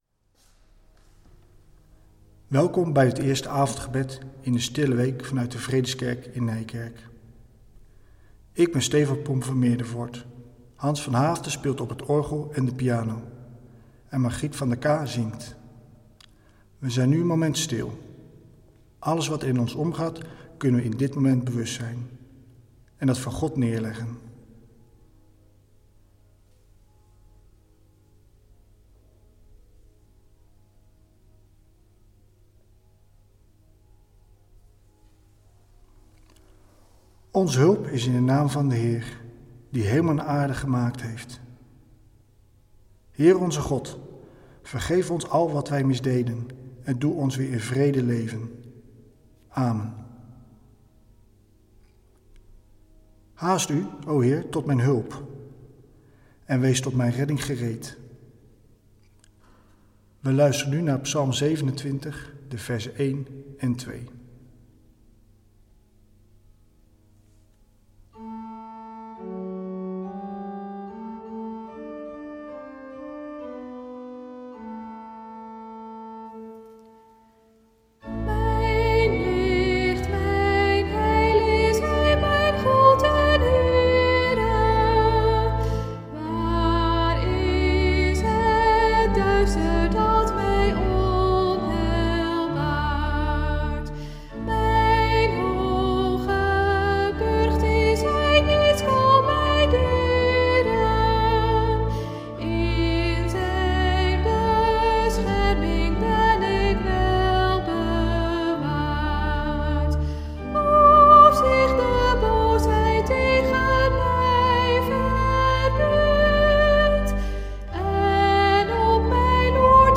Zij zullen van tevoren opgenomen worden.
orgel en piano
sopraan
In de Stille Week zijn gemeenteleden lector.